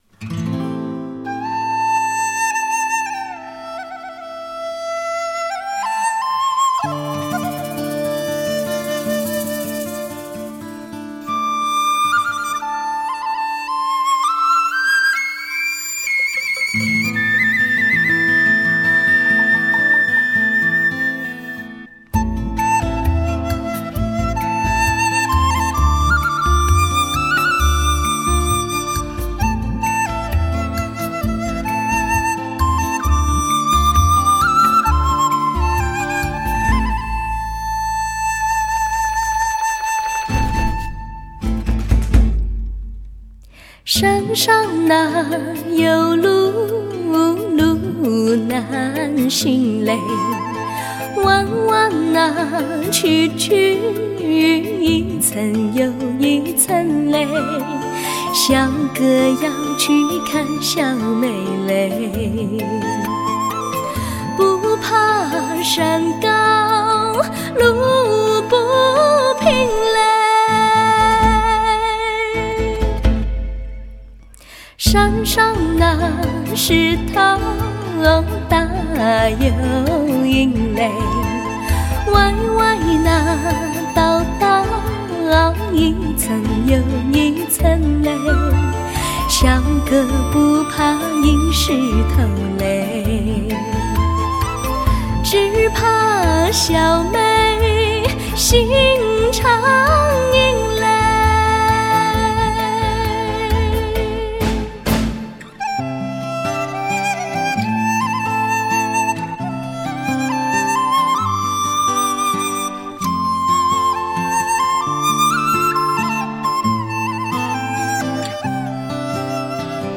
优质甜美女声
这些曾与大家的生命共荣共生的老歌，经过重新配器改编，带出隽永纯真的天籁之韵。